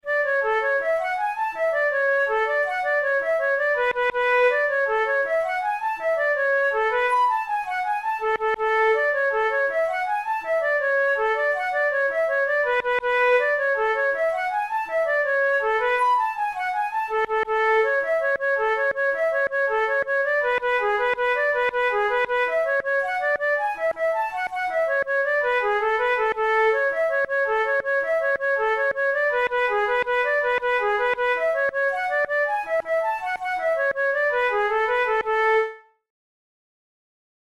InstrumentationFlute solo
KeyA major
Time signature6/8
Tempo108 BPM
Jigs, Traditional/Folk
Traditional Irish jig